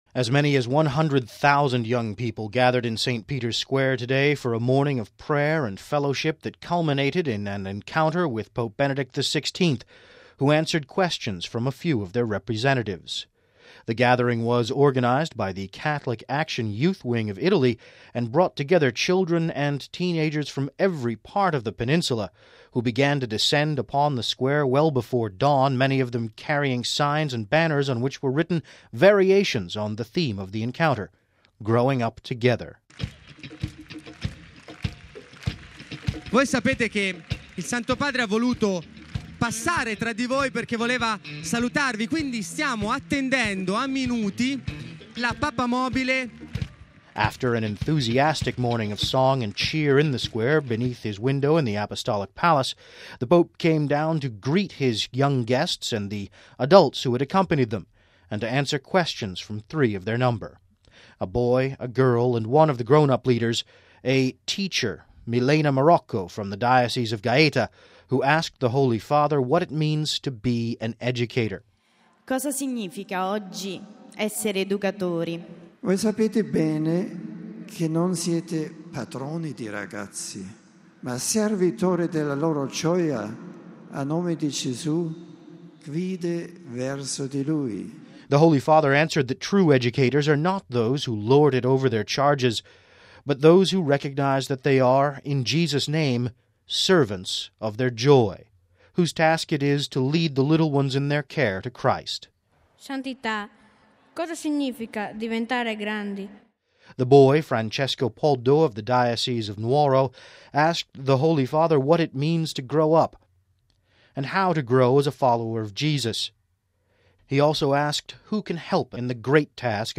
As many as 100 thousand young people gathered in St. Peter’s Square Saturday for a morning of prayer and fellowship that culminated in an encounter with Pope Benedict XVI, who answered questions from a few of their representatives.